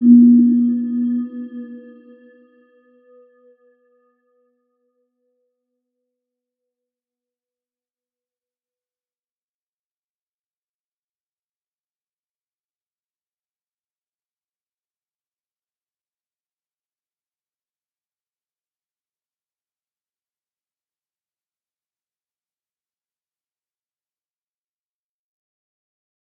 Round-Bell-B3-p.wav